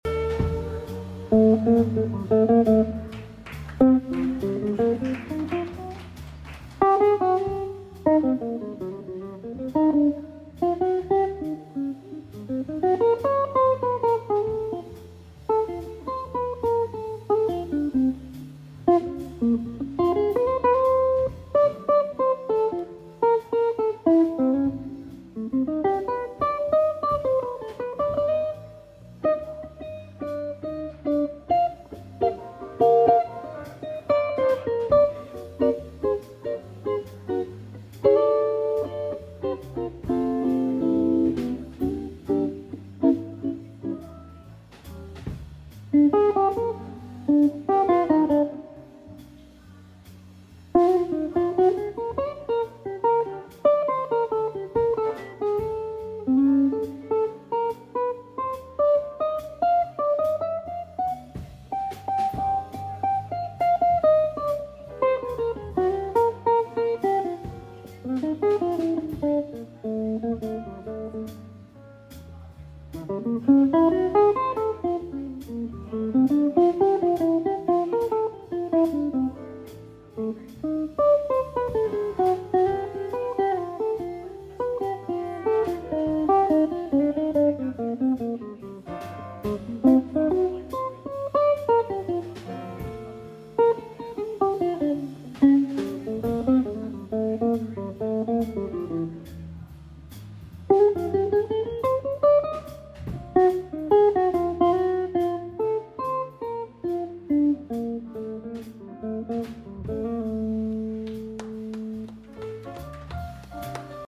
jazz guitar
Here's two choruses in the 'meditative' improvisational mode by the author from a recent AK jamm session.